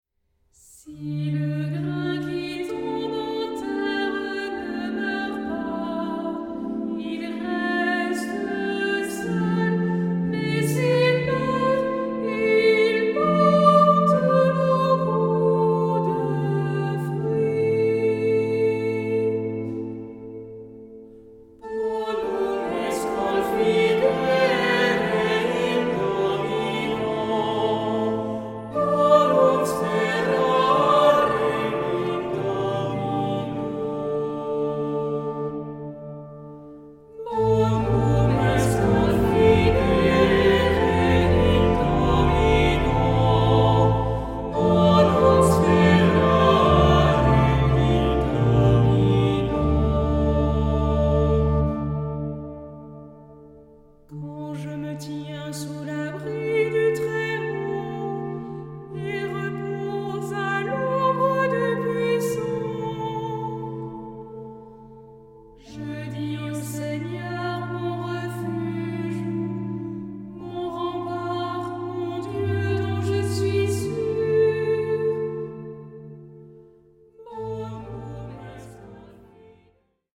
Genre-Style-Form: troparium ; Psalmody ; Sacred
Mood of the piece: collected
Type of Choir: SATB  (4 mixed voices )
Instruments: Organ (1)
Tonality: D minor